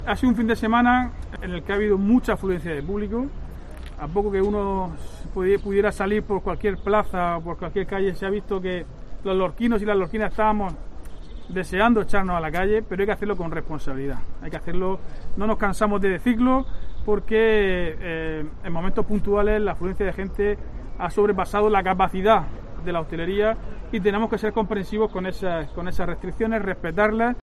Diego José Mateos, alcalde de Lorca sobre terrazas